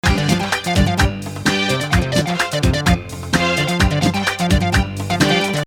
Pentatonic fragment
not an arabic jins here--western influence!